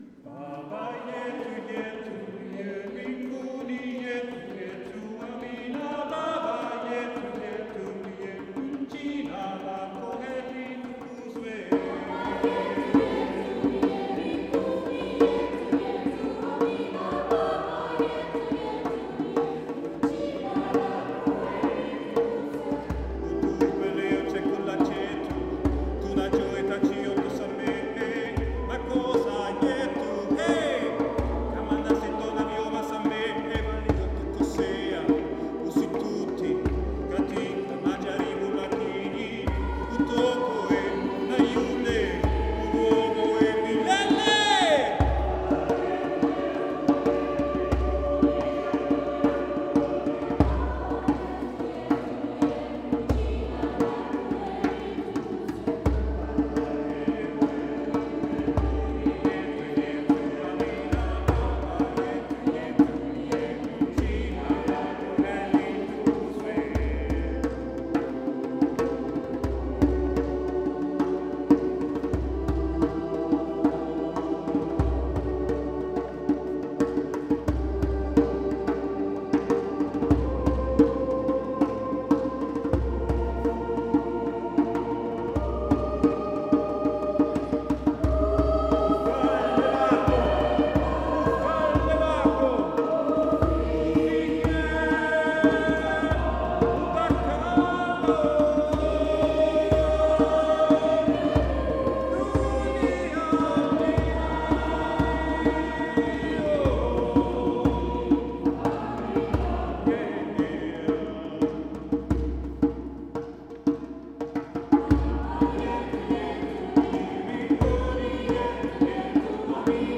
Mit rhythmischen Bewegungen zu afrikanischen Liedern begeisterte der Chor unserer Pfarre
Die afrikanischen Lieder aus dem Gottesdienst